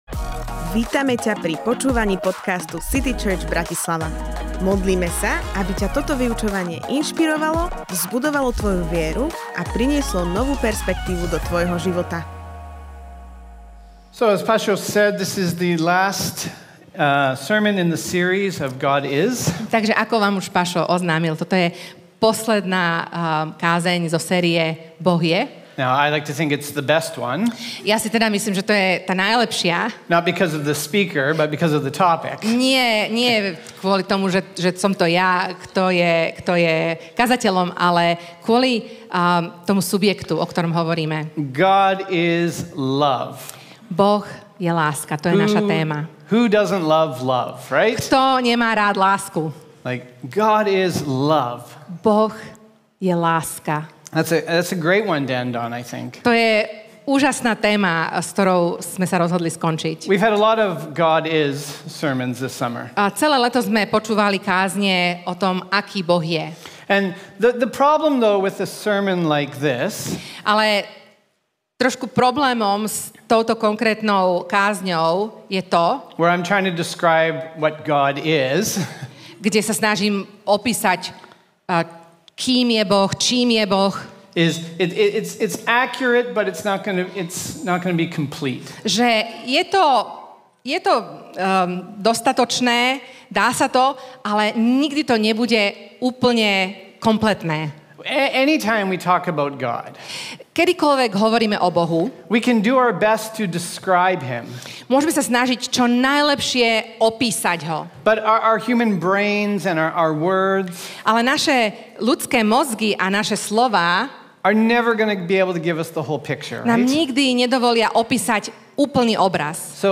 Táto kázeň je pozvaním zamyslieť sa, čo to pre nás znamená v každodennom živote.